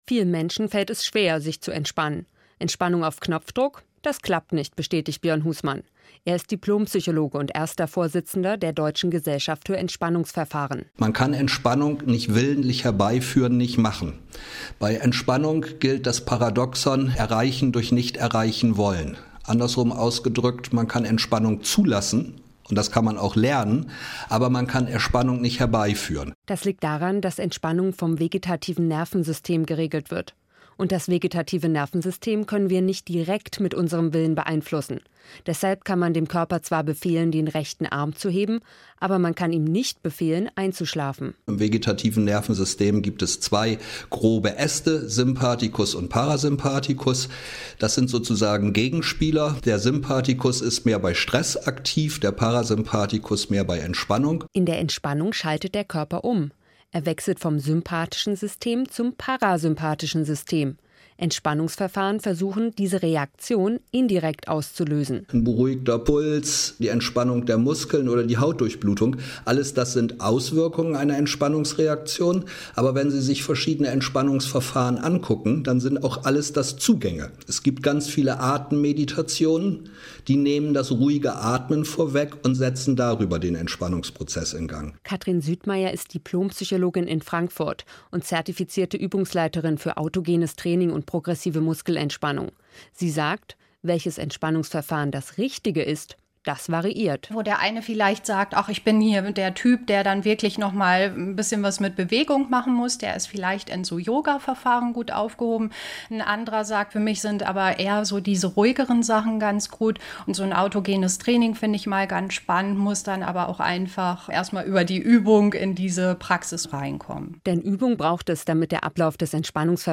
Radio-Beitrag auf hr-iNFO: Entspannung auf Knopfdruck – geht das?